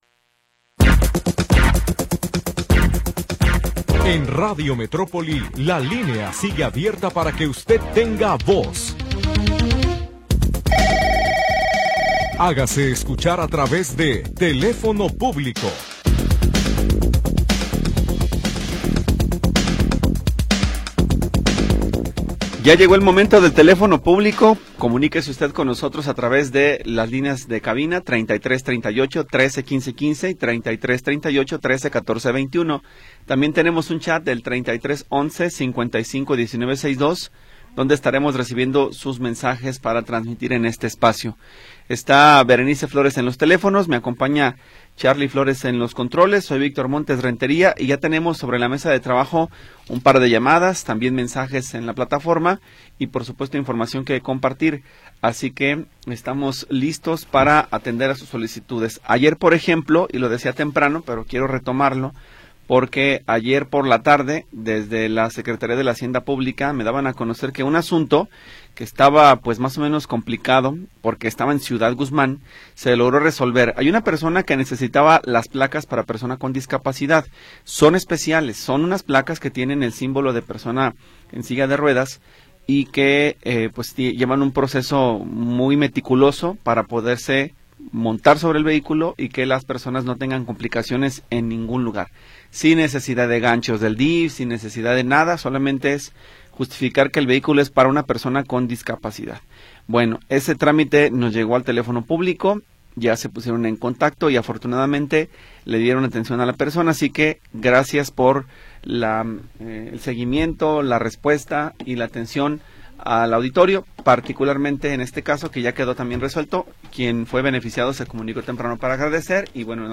Programa transmitido el 27 de Noviembre de 2025.